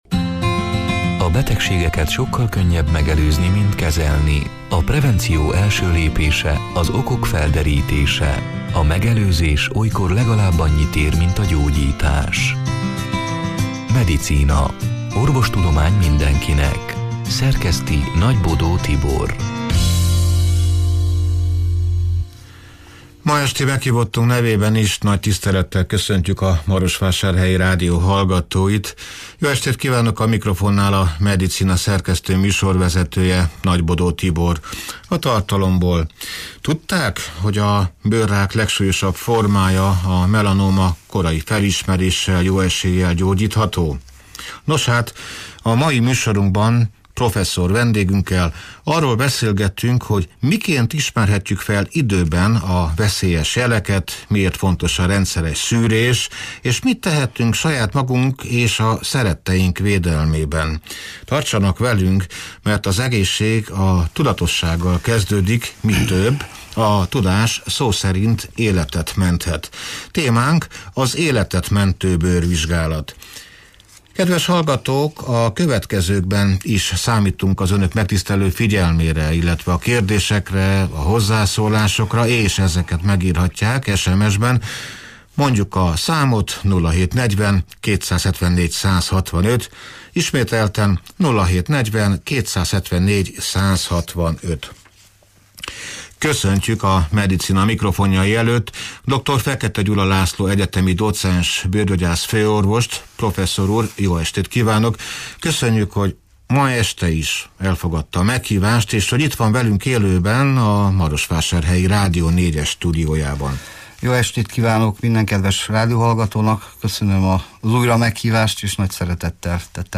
(elhangzott: 2025. május 28-án, szerdán este nyolc órától élőben)